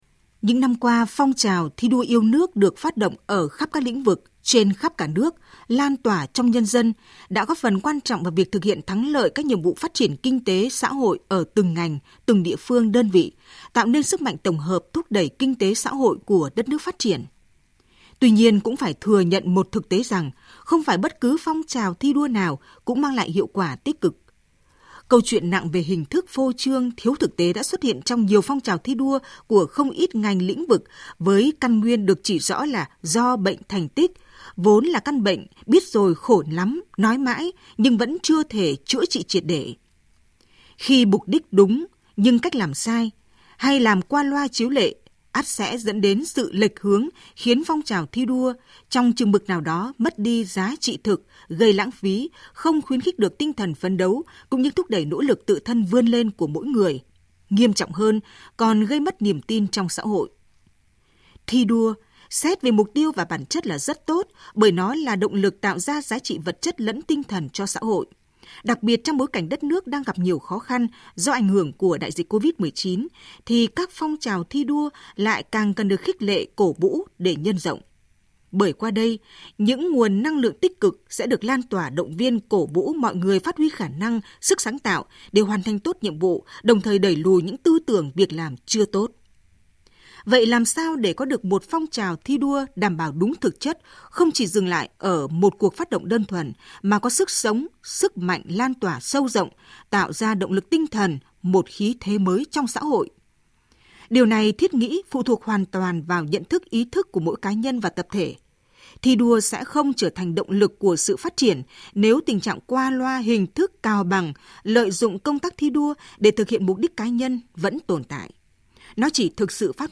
THỜI SỰ Bình luận VOV1